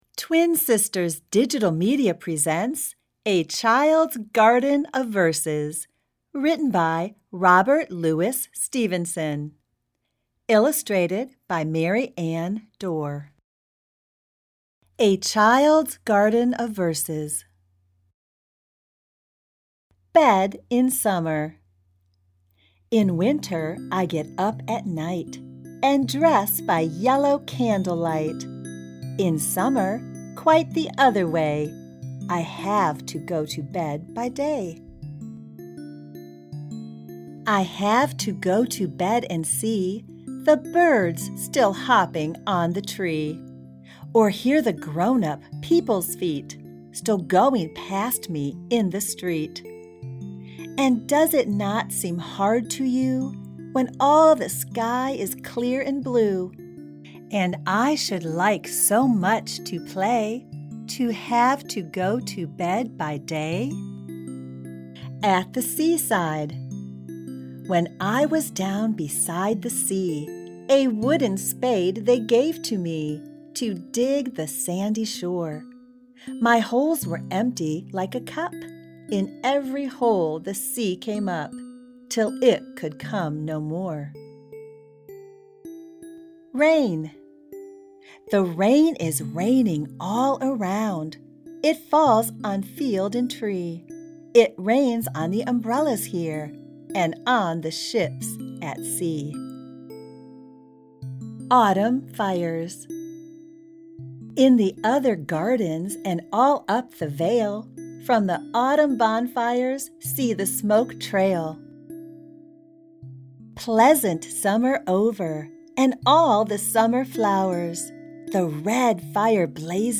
Reading A Child's Garden of Verses